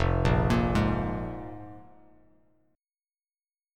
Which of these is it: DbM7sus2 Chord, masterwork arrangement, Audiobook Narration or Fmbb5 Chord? Fmbb5 Chord